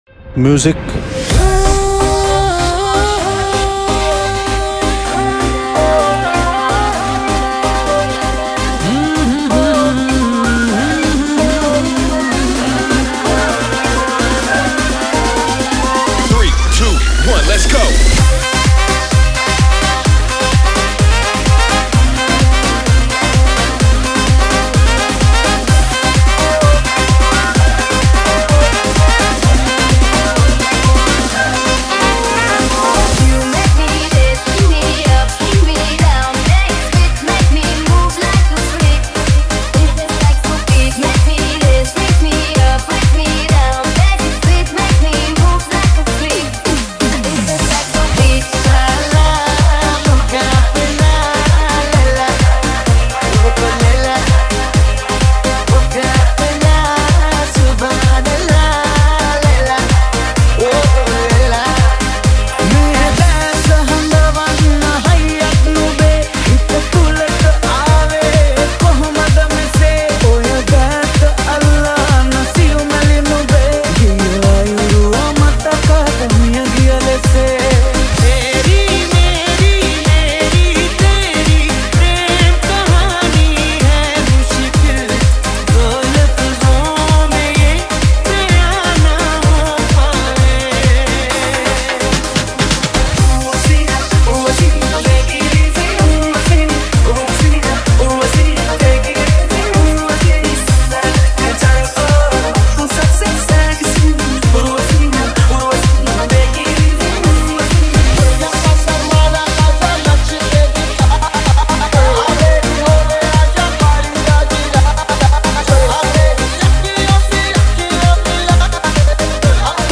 Bollywood Mashup | Party Songs | Hindi Mashup 2020.
This is a full happening remix.